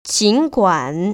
[jĭnguăn] 진구안  ▶